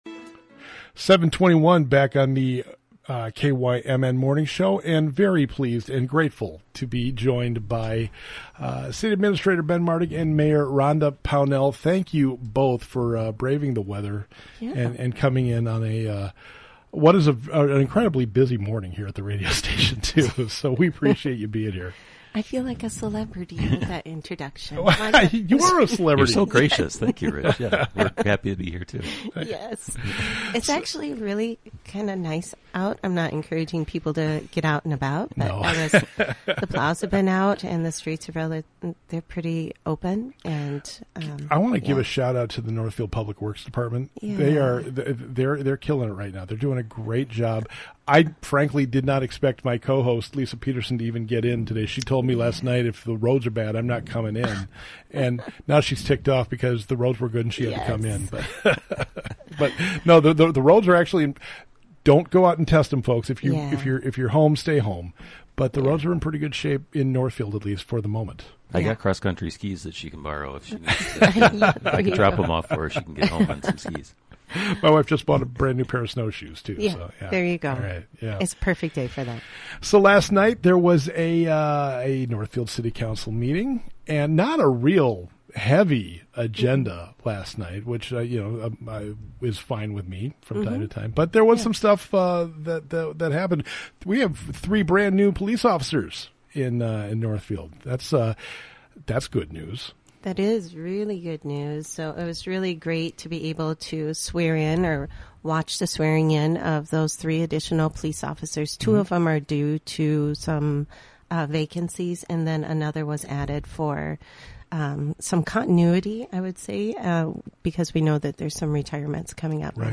Mayor Rhonda Pownell and Ben Martig discuss Northfield City Council meeting